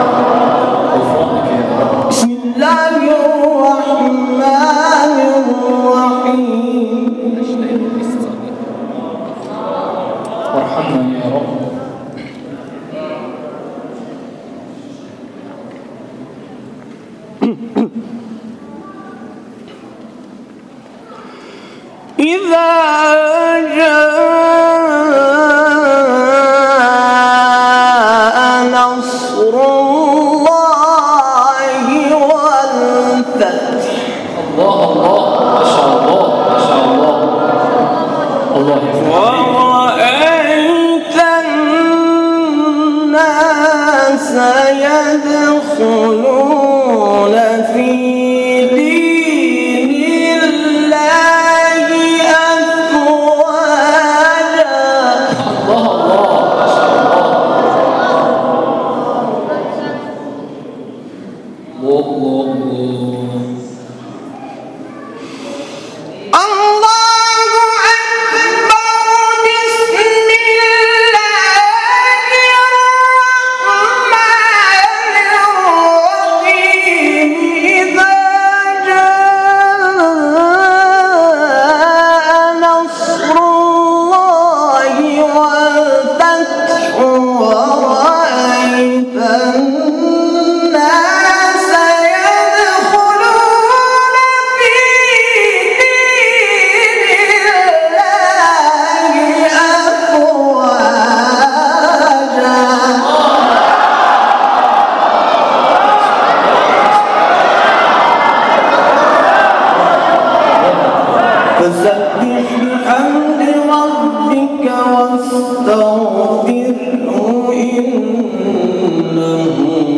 برچسب ها: قاری بین المللی ، تلاوت های مجلسی ، اردبیل